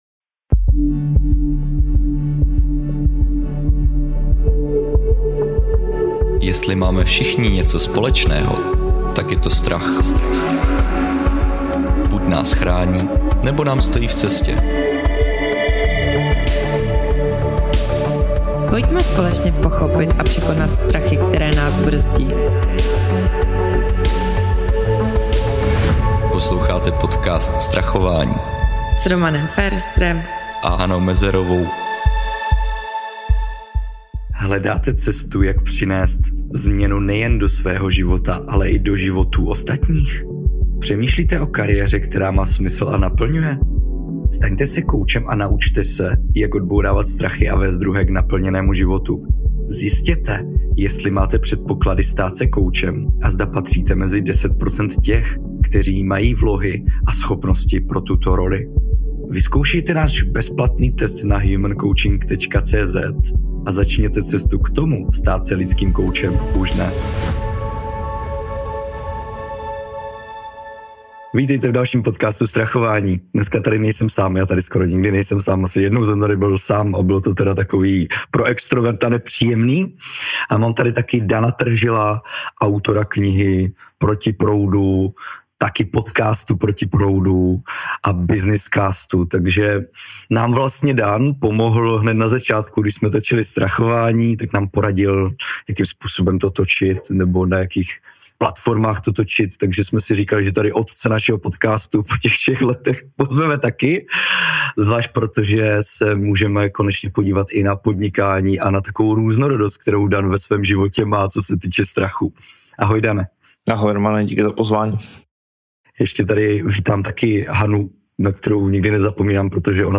Poslechněte si inspirativní rozhovor plný upřímnosti, humoru a užitečných tipů na zvládání strachu.